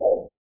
dogspacebark3.mp3